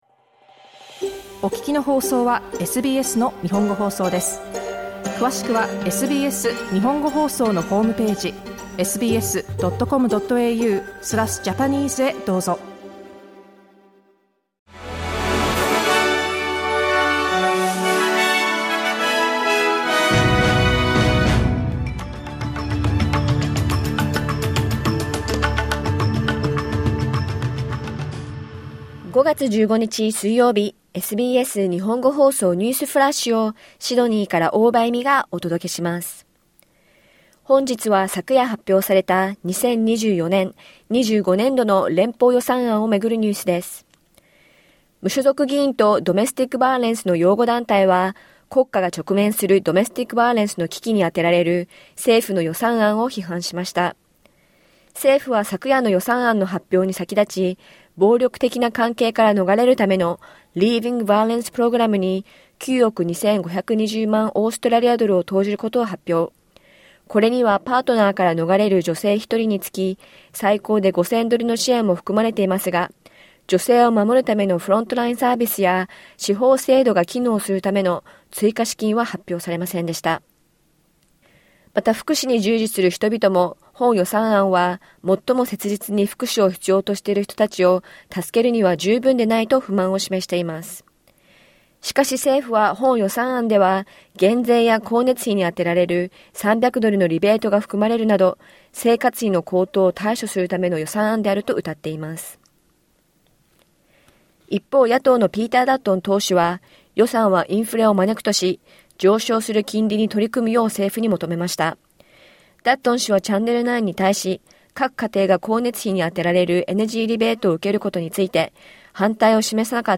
昨夜発表された2024年/25年度の連邦予算案をめぐるニュースです。